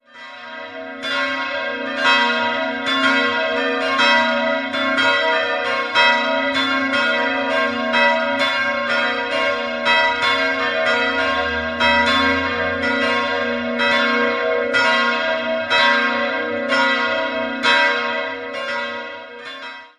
Die unverputzten Bruchsteine und der markante Turm prägen das äußere Erscheinungsbild. 3-stimmiges Paternoster-Geläute: b'-c''-d'' Alle Glocken wurden 1959 von Friedrich Wilhelm Schilling in Heidelberg gegossen.